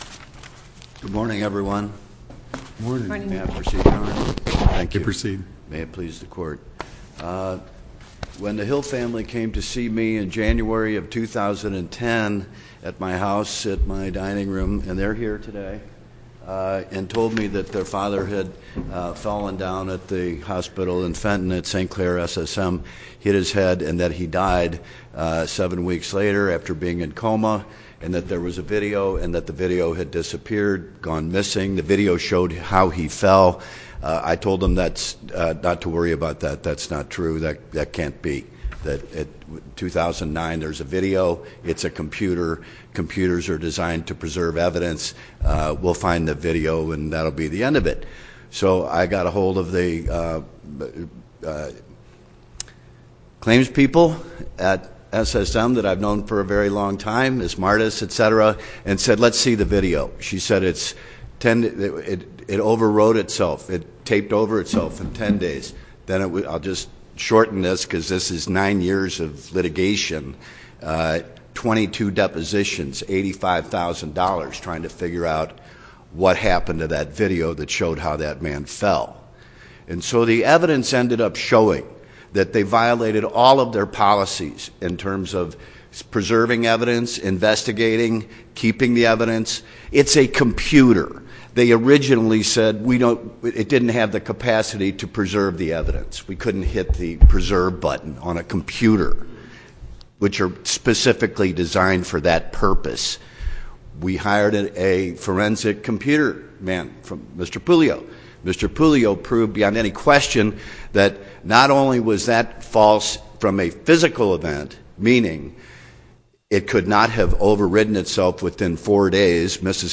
link to MP3 audio file of oral arguments in SC97287